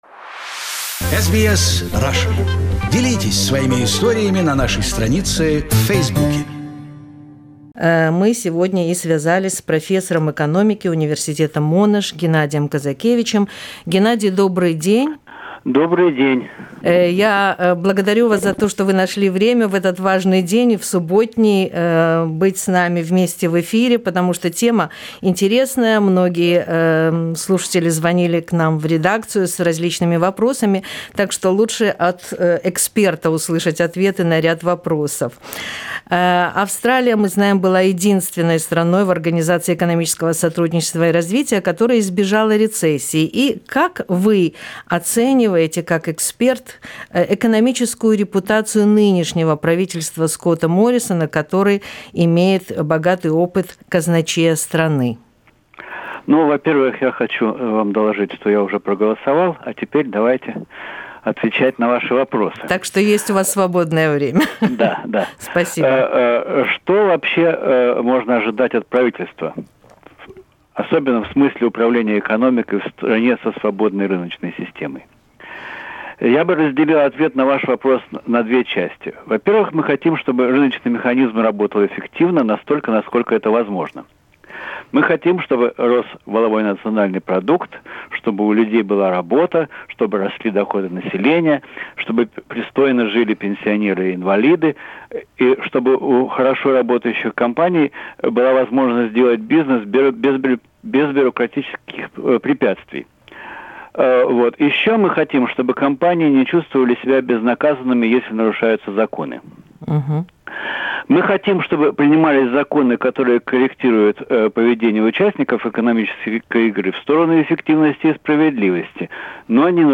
Pre-election conversation